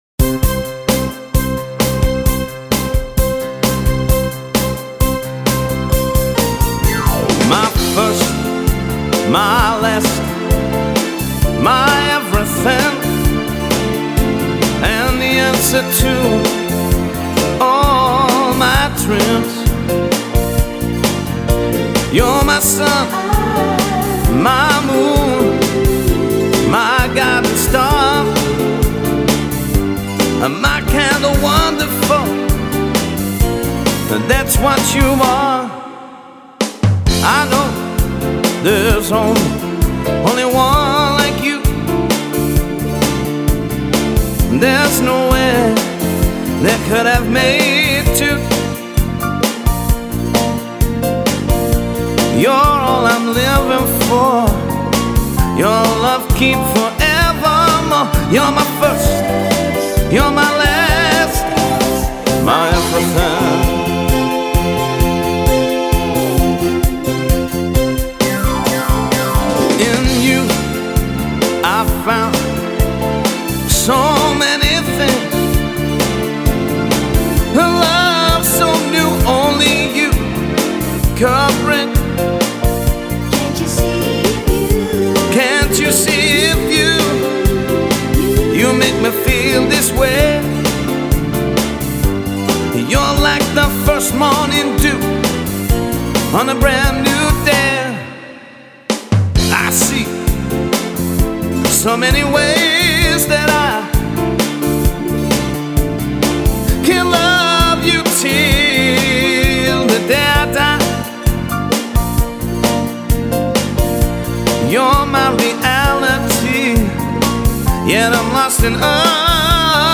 Superb Soul & Motown Show